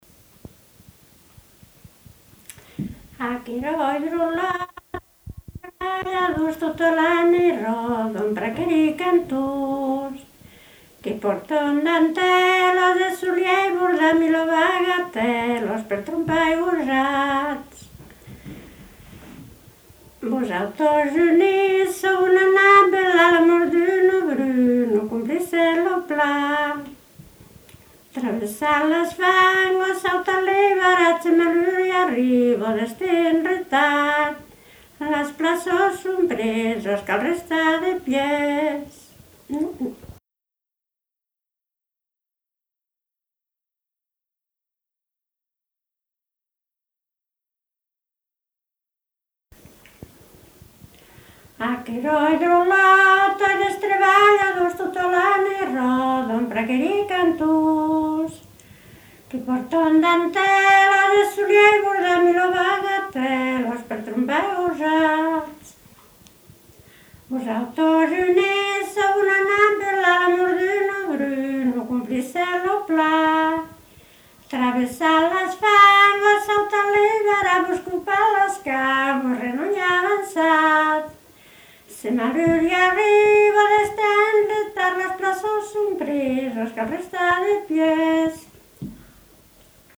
Aire culturelle : Couserans
Lieu : Esqueing (lieu-dit)
Genre : chant
Type de voix : voix de femme
Production du son : chanté
Notes consultables : Le chant est interprété deux fois.